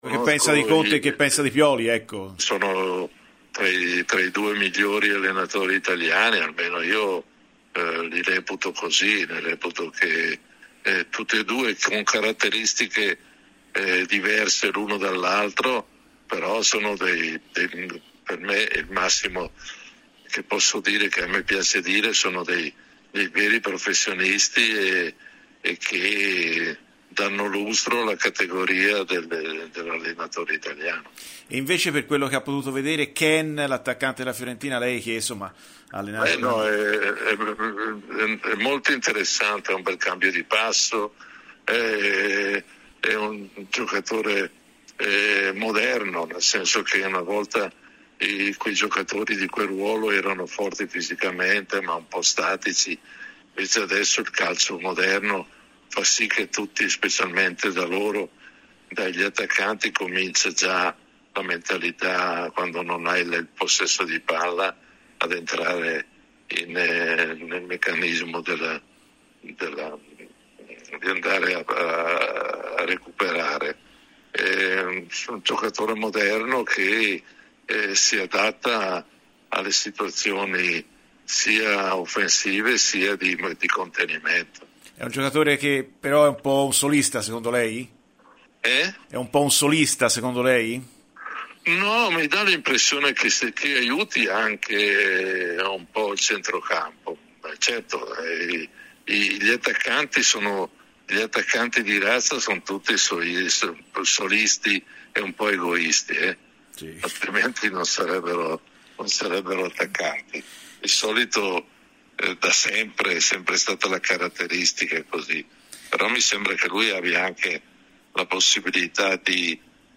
Ottavio Bianchi, ex allenatore di Fiorentina e Napoli, è intervenuto ai microfoni di Radio FirenzeViola durante la trasmissione Viola Amore Mio per parlare della sfida in programma sabato allo Stadio Artemio Franchi tra i viola e gli azzurri.